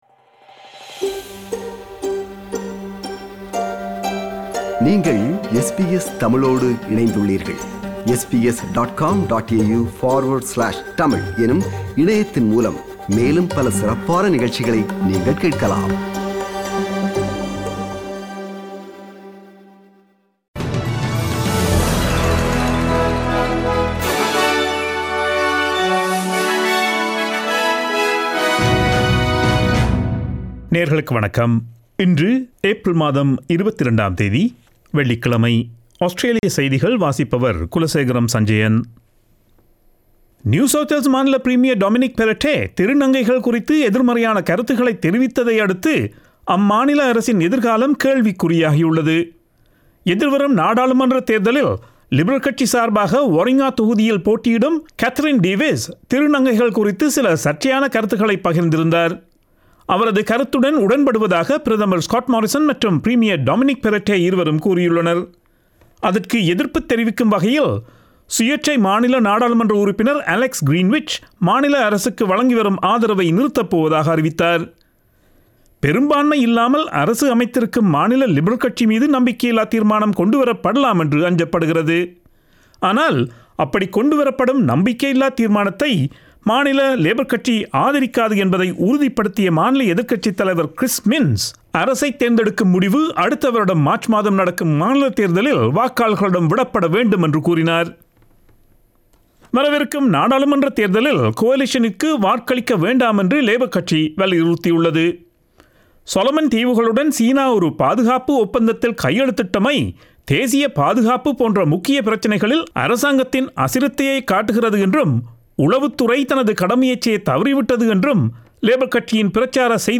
Australian news bulletin for Friday 22 April 2022.